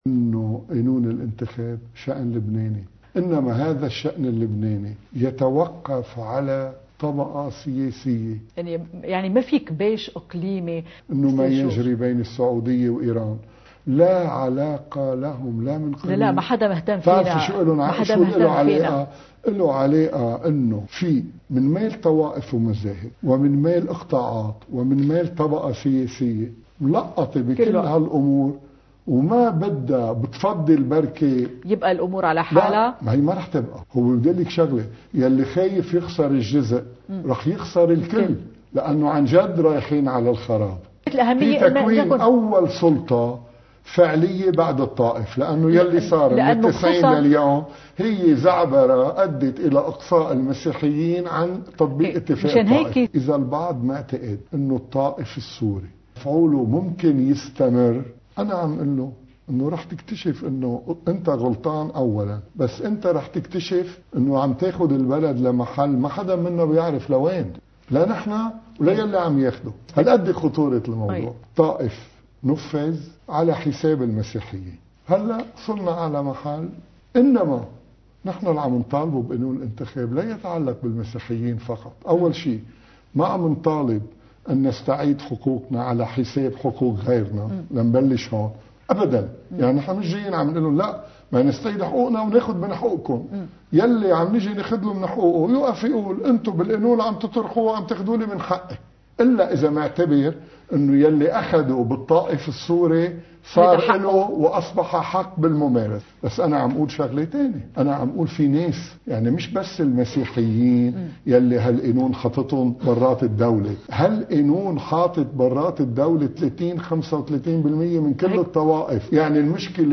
مقتطف من حديث النائب جورج عدوان ضمن برنامج “وجهاً لوجه” على قناة “تلفزيون لبنان”